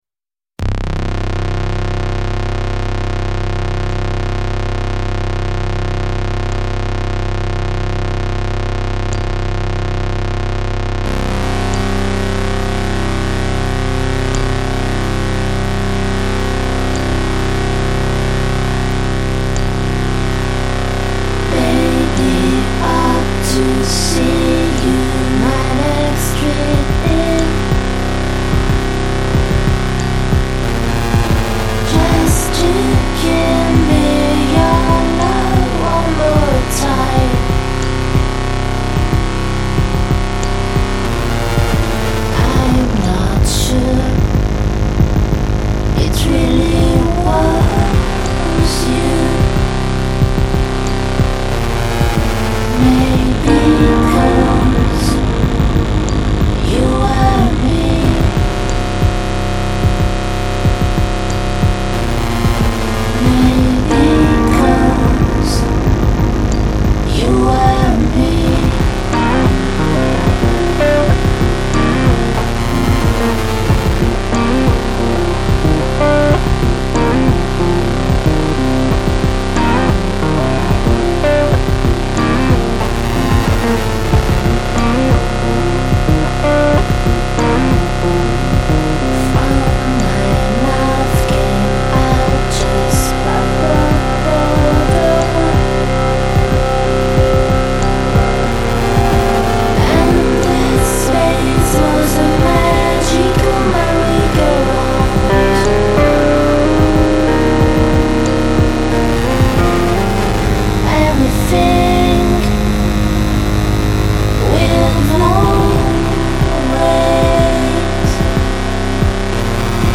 electonic duo
voices, synths
guitars, electronics.